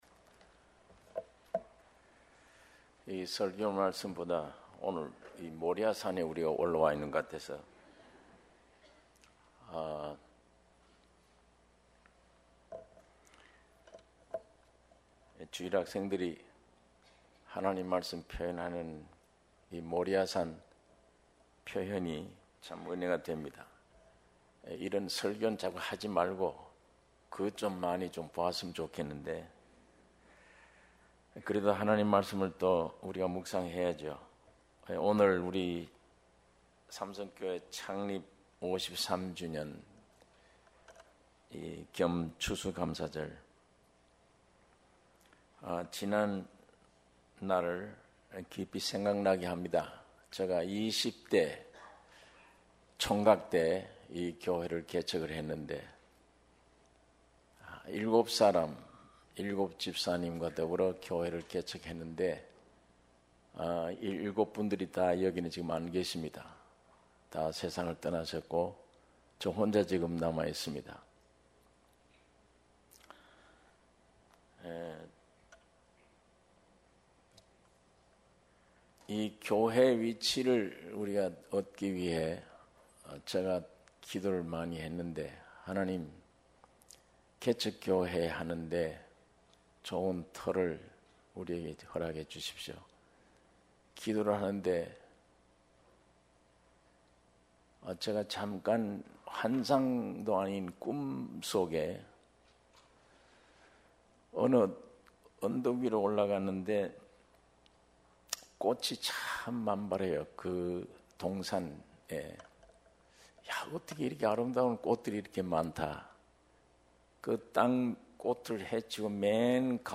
주일예배 - 시편 103장 1절-2절